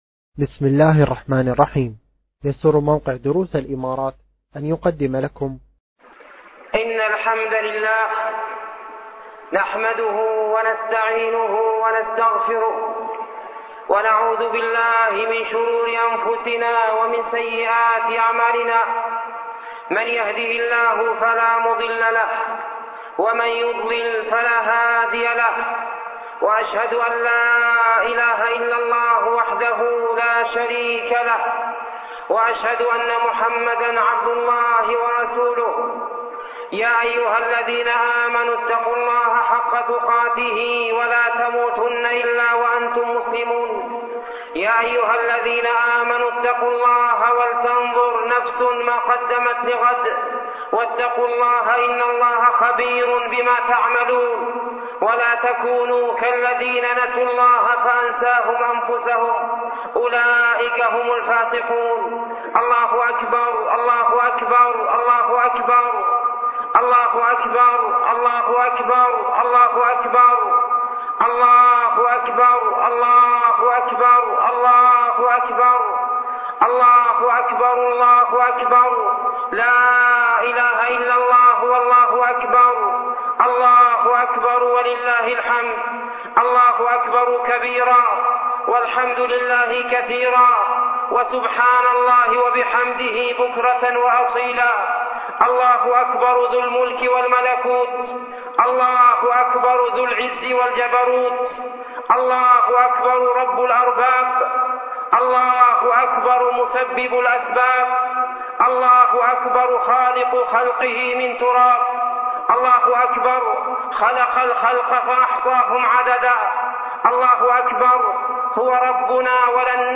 خطب - Page 118 of 890 - موقع دروس الإمارات